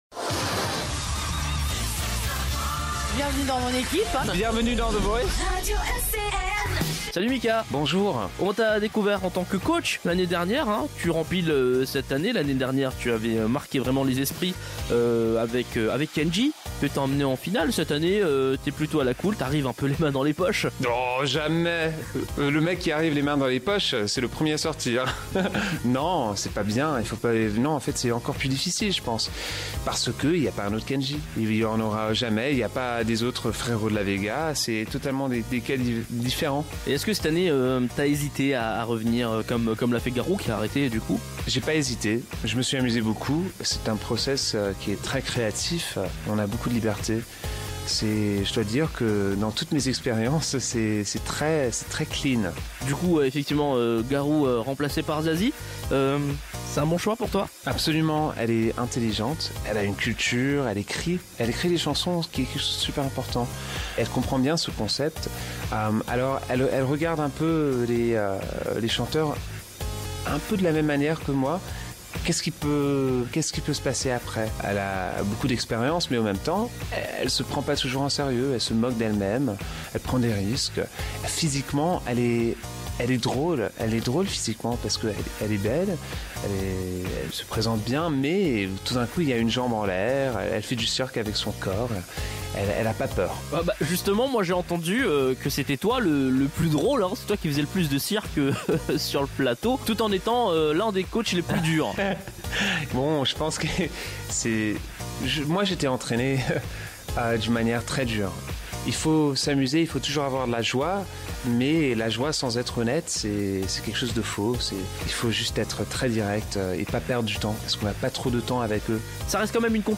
Mika - Interview Radio ECN - 11.01.2015